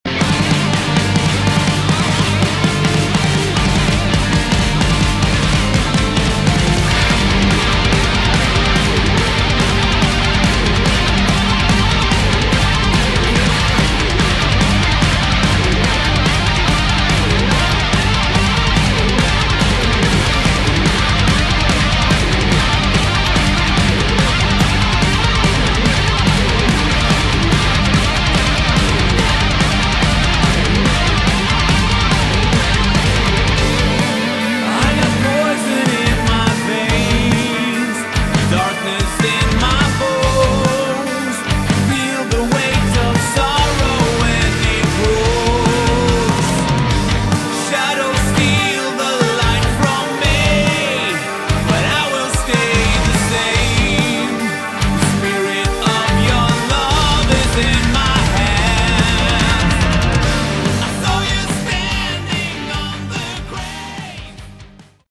Category: Melodic Metal
rhythm, lead and acoustic guitars
drums and percussion
vocals
bass guitar
A great harder edged melodic rock.